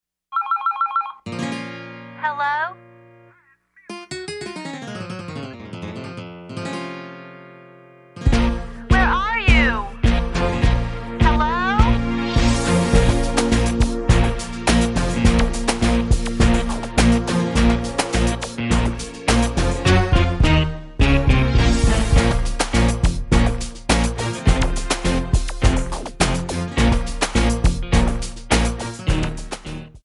Audio Backing tracks in archive: 9793
Buy With Backing Vocals.
Buy With Lead vocal (to learn the song).